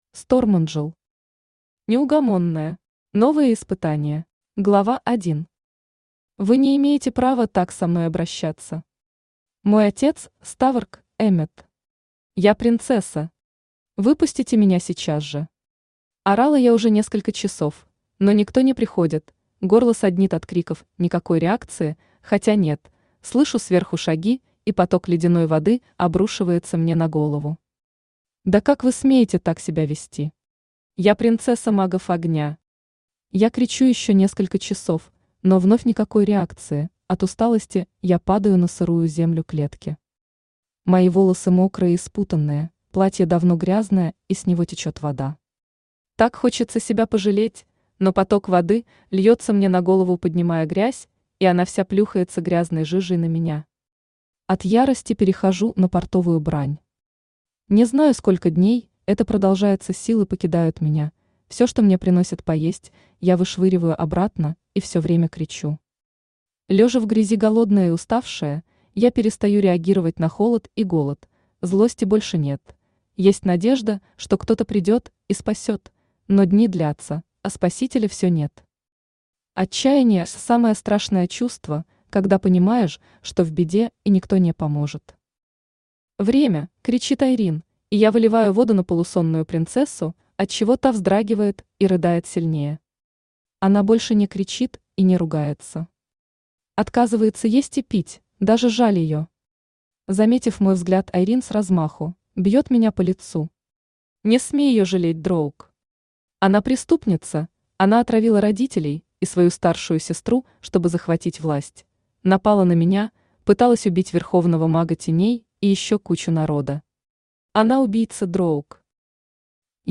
Аудиокнига Неугомонная. Новые испытания | Библиотека аудиокниг
Читает аудиокнигу Авточтец ЛитРес.